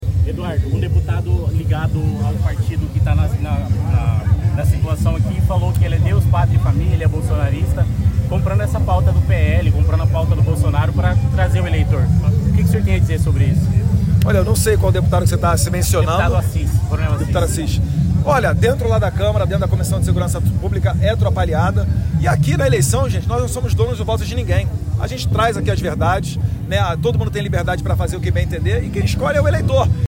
Em entrevista concedida em visita à Várzea Grande no último sábado (28), Eduardo Bolsonaro disse ainda que “nas eleições municipais não somos donos do voto de ninguém”, referindo-se ao fato de o deputado Coronel Assis apoiar o candidato à reeleição para prefeito, Kalil Baract (MDB).